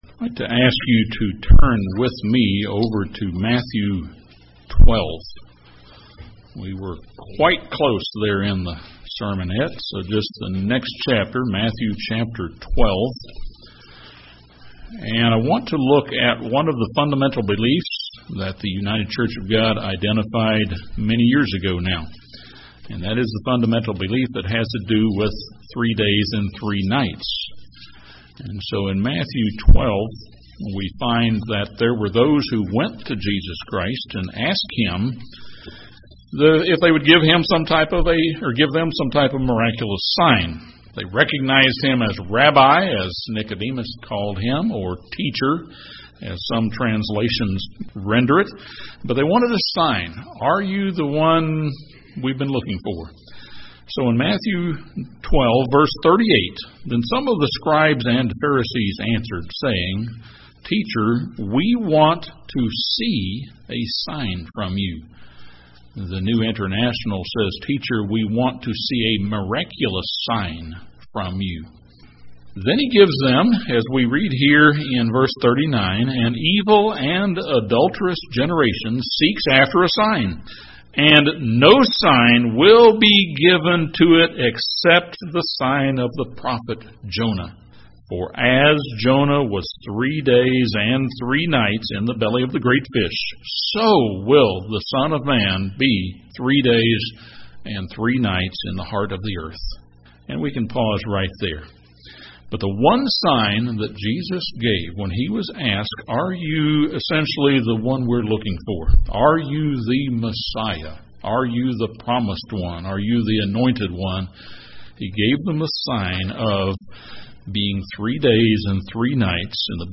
Why, then, does today's Christianity insist that Christ spent only a day and a half in the tomb? In this sermon, we see what the biblical record really shows concerning the time that Christ spent in the tomb before his resurrection.